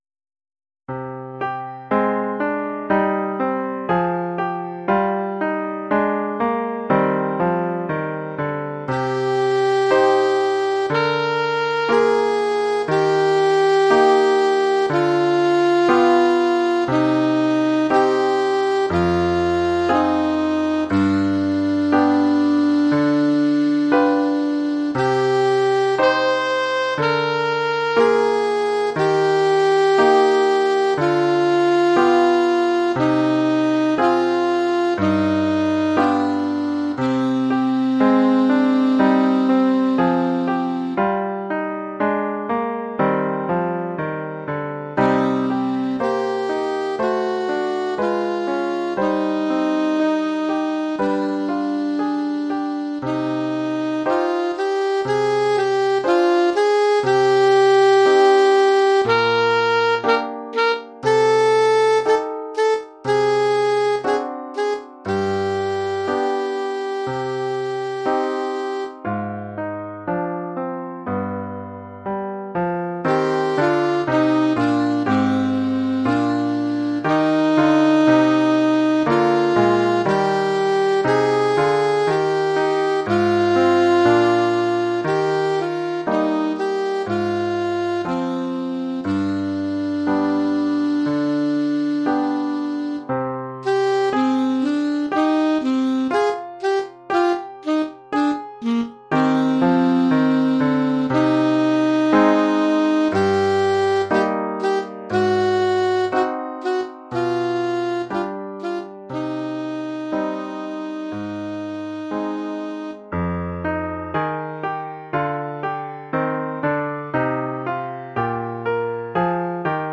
Oeuvre pour saxophone alto et piano.